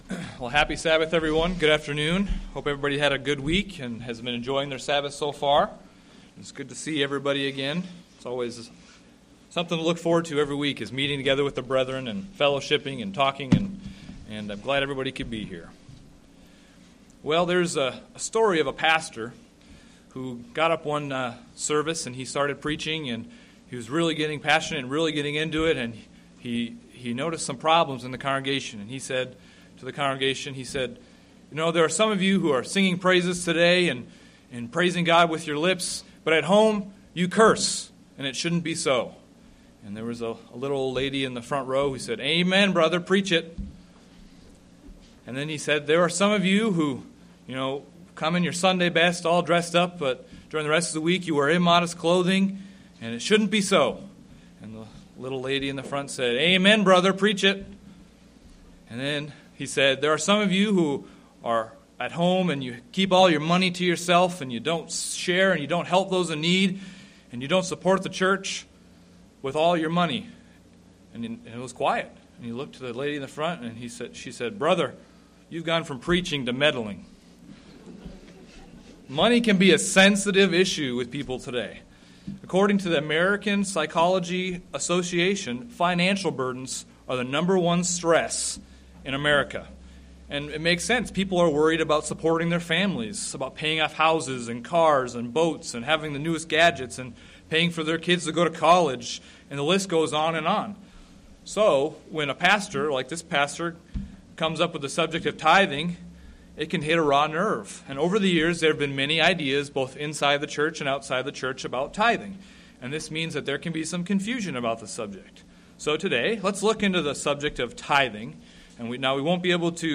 Given in Springfield, MO
UCG Sermon Studying the bible?